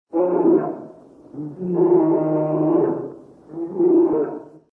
Descarga de Sonidos mp3 Gratis: oso 1.